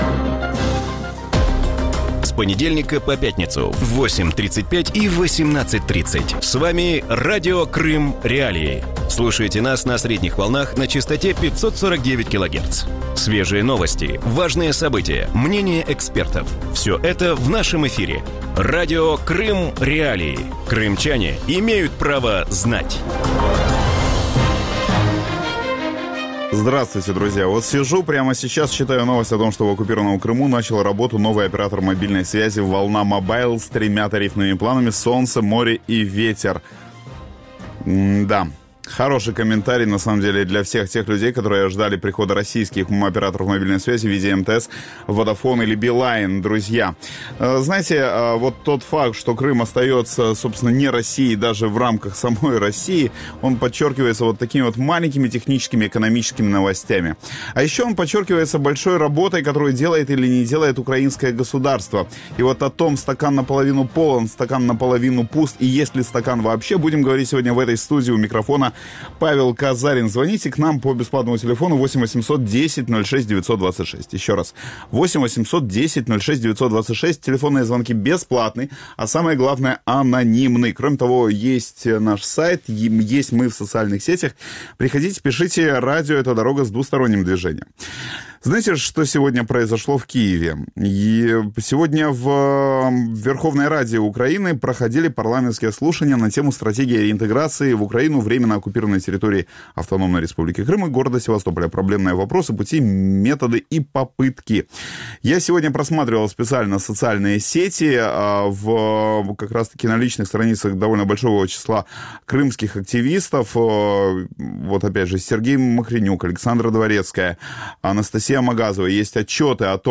В вечернем эфире Радио Крым.Реалии обсуждают законодательные инициативы украинской власти, связанные с проблемами переселенцев из Крыма. Держит ли украинский парламент и правительство руку на пульсе, с какими проблемами сталкиваются вынужденные переселенцы из Крыма и как их можно решить?